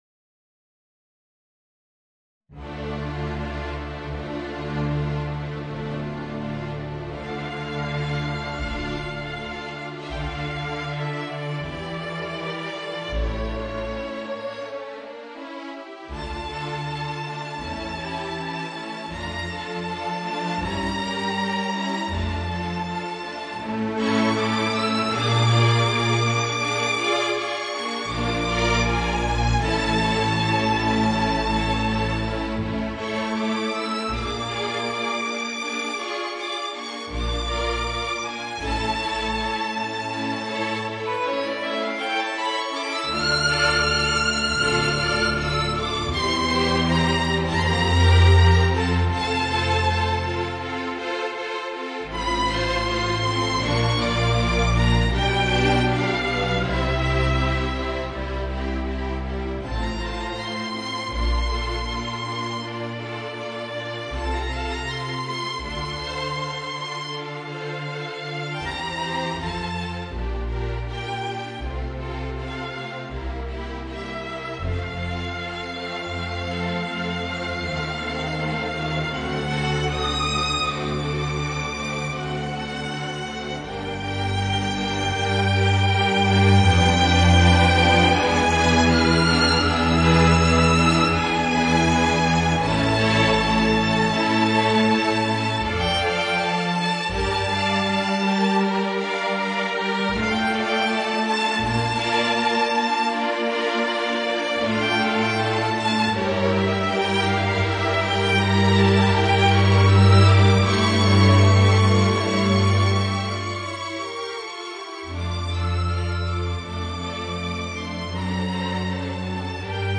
Voicing: Trombone and String Quintet